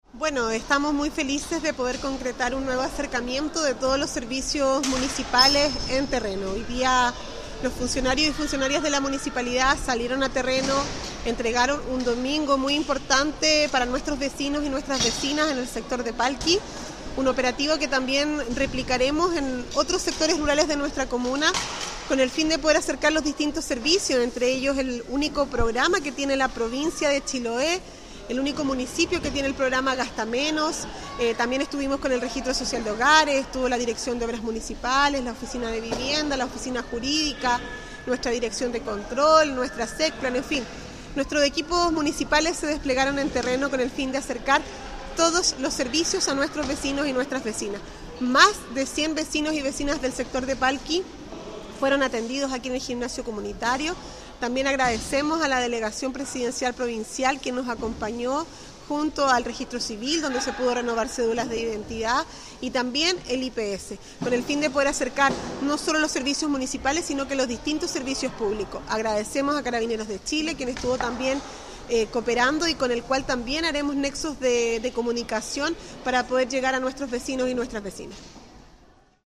Al respecto la alcaldesa Javiera Yañez señaló:
ALCALDESA-MUNICIPIO-EN-TERRENO-.mp3